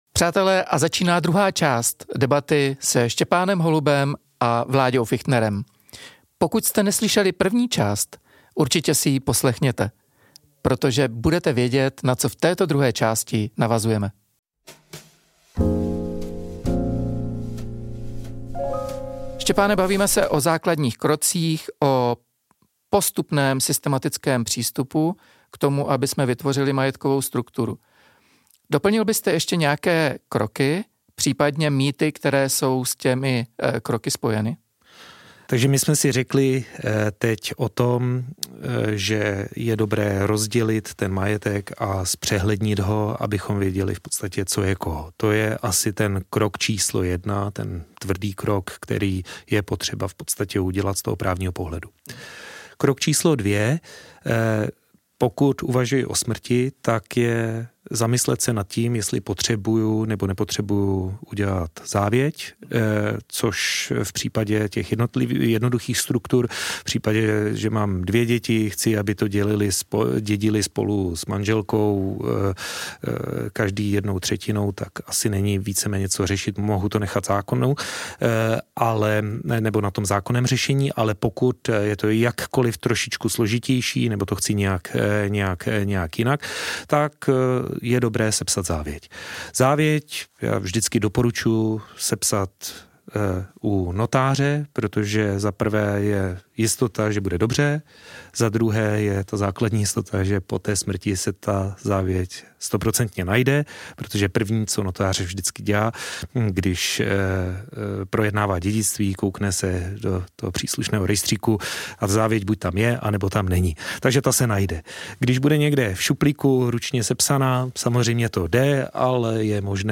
Pokračování rozhovoru